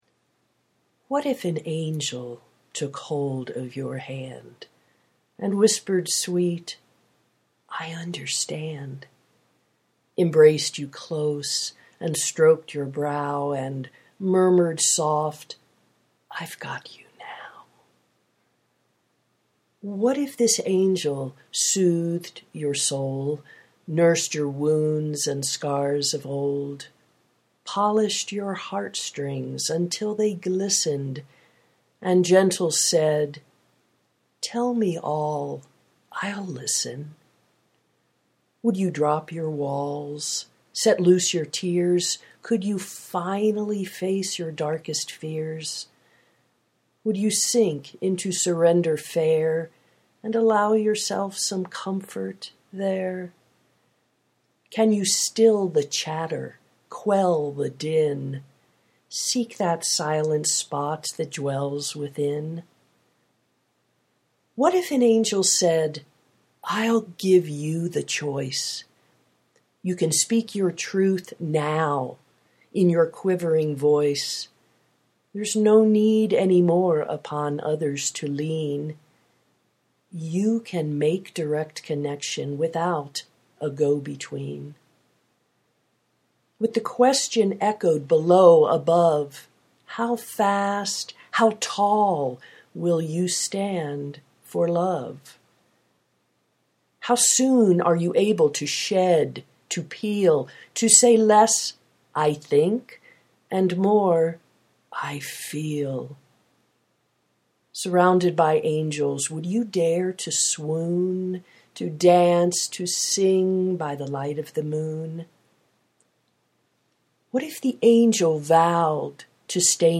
I revisited this rhyming poem I wrote in 2011, and realized that although someone else had created a charming video featuring my words here, it was clearly time to bring my own voice to this timeless message as well.